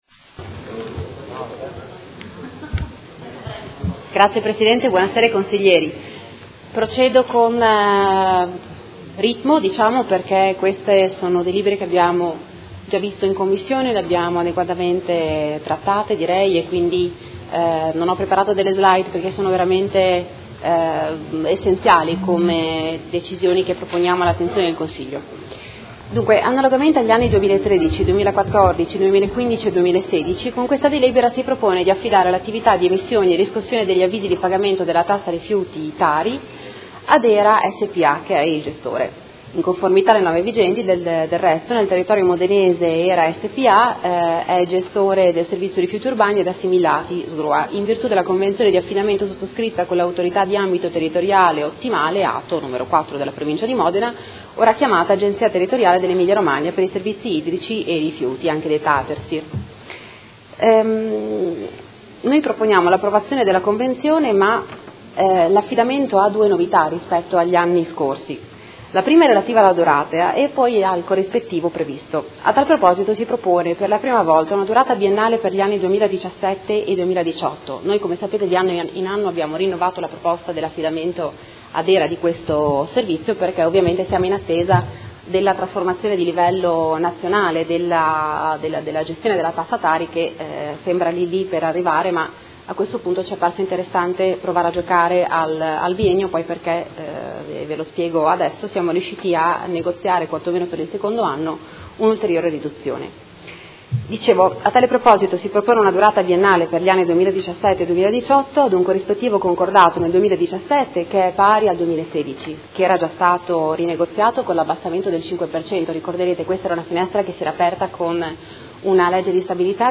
Seduta del 30/03/2017. Proposta di deliberazione: Convenzione per l’affidamento della gestione della riscossione del Tributo comunale sui rifiuti TARI anni 2017-2018 e per la regolamentazione della fatturazione e dei pagamenti del servizio di gestione dei rifiuti urbani ed assimilati (SGRUA) anno 2017
Audio Consiglio Comunale